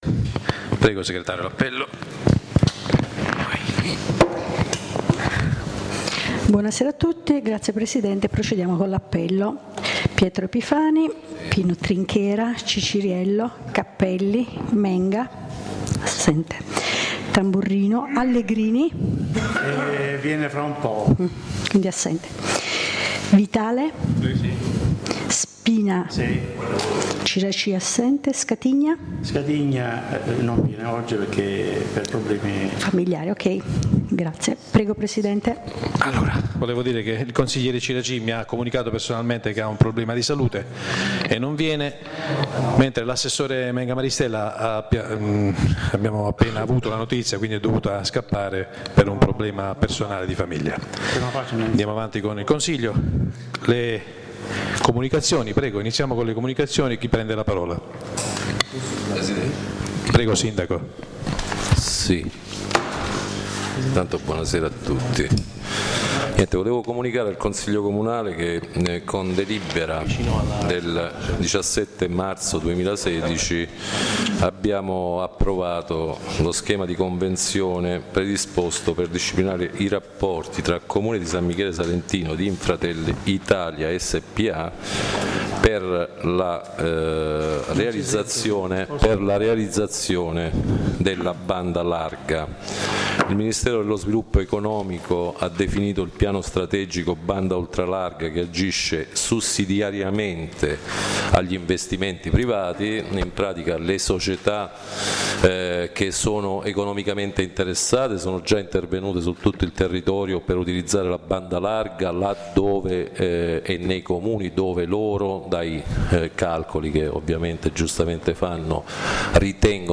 La registrazione audio del Consiglio Comunale di San Michele Salentino del 07/06/2016